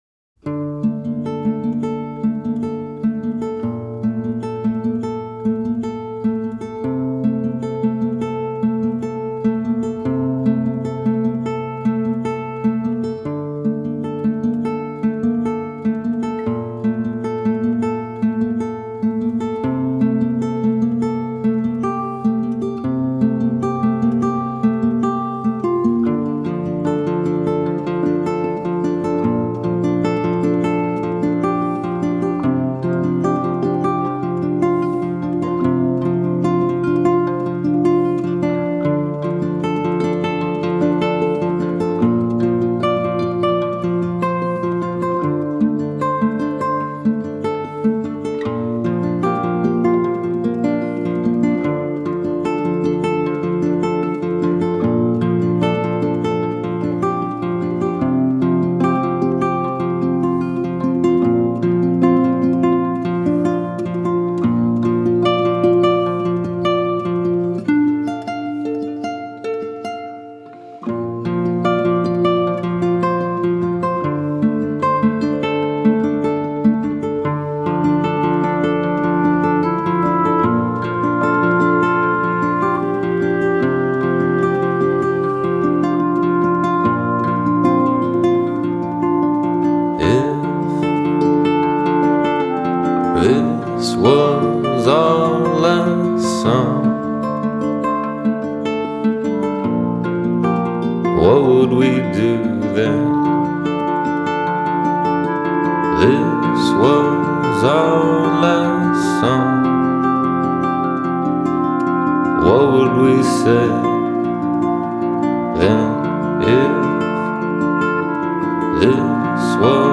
ensemble of multiple strings